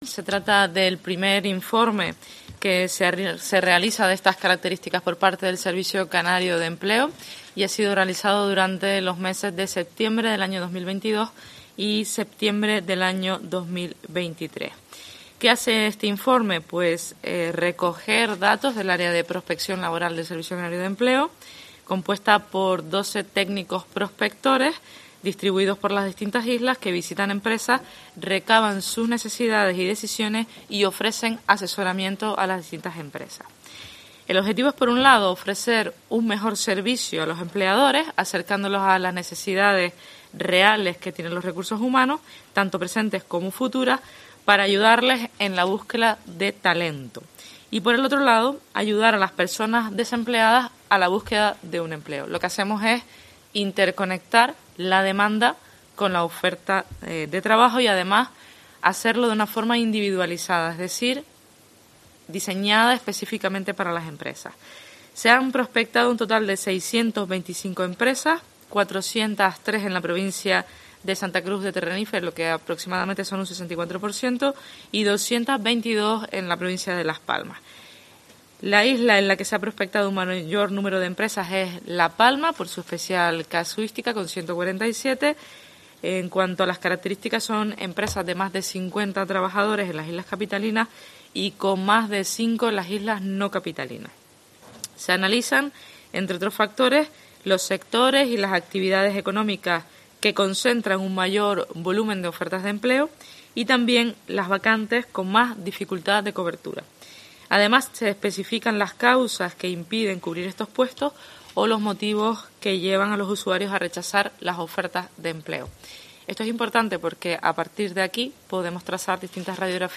Jéssica de León, consejera de Turismo y Empleo del Gobierno de Canarias